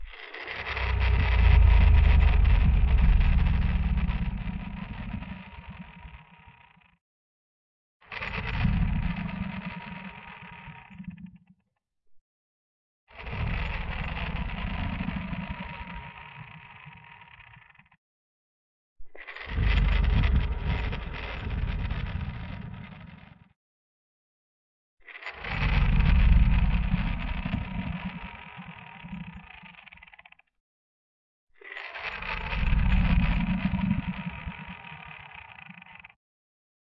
吓人的怪物Rawr SFX
Tag: 生物 恐怖 动物 数字 处理 隆隆声 爬行 咆哮 怪物 OWI 咆哮 可怕 RAWR